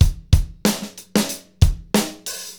BEAT 7 9306L.wav